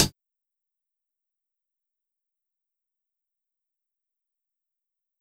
Closed Hat (CHUM).wav